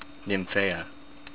NIM-fee-uh